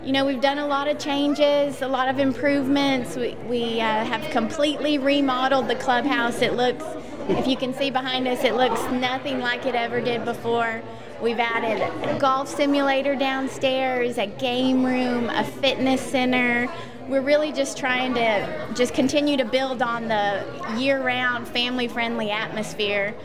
WCLU News spoke with co-owners